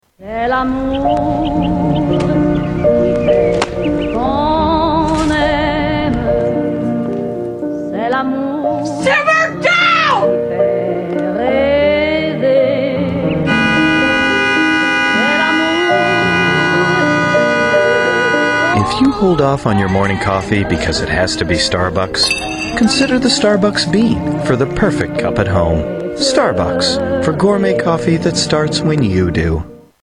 Funny Starbucks Commercial
Tags: Starbucks Starbucks Commercial clips Starbucks clips Starbucks Ads Commercial